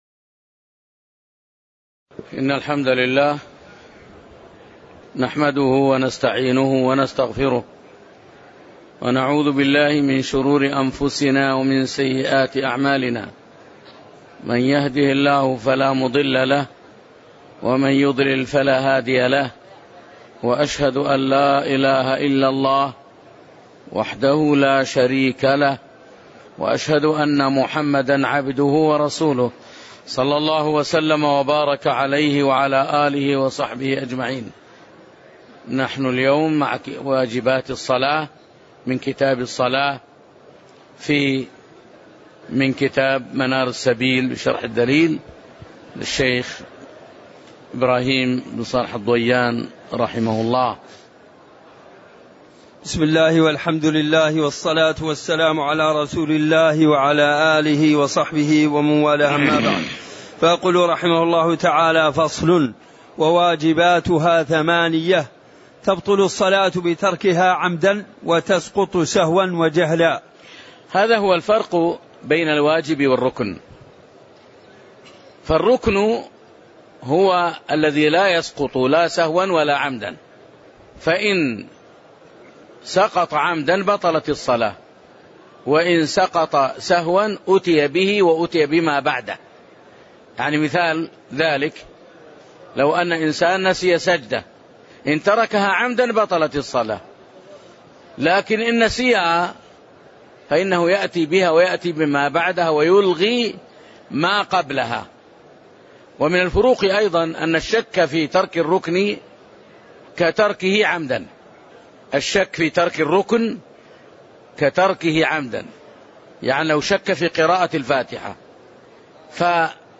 تاريخ النشر ١٧ ذو القعدة ١٤٣٨ هـ المكان: المسجد النبوي الشيخ